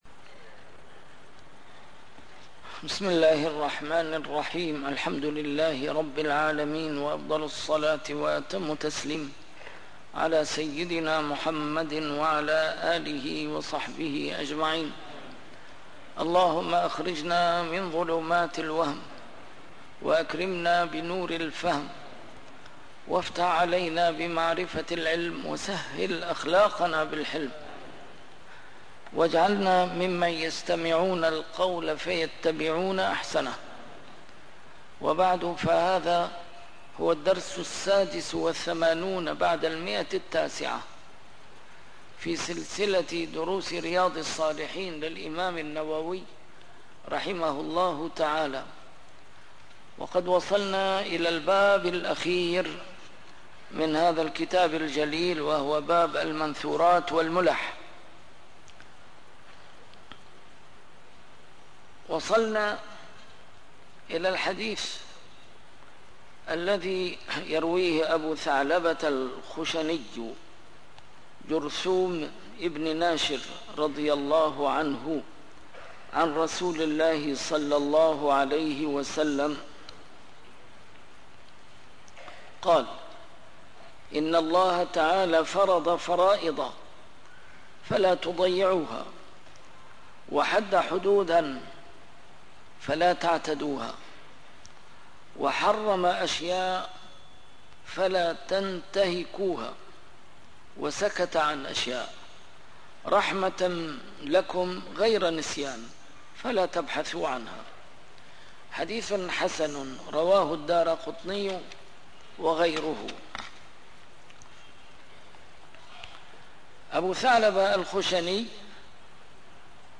A MARTYR SCHOLAR: IMAM MUHAMMAD SAEED RAMADAN AL-BOUTI - الدروس العلمية - شرح كتاب رياض الصالحين - 986- شرح رياض الصالحين: بابُ المنثورات والمُلَح